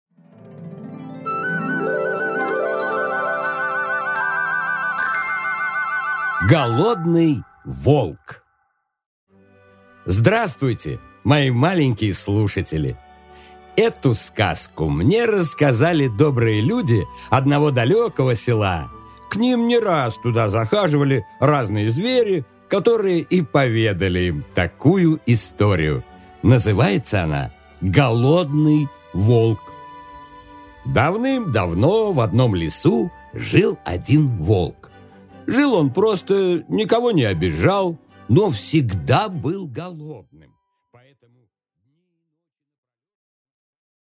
Аудиокнига Голодный волк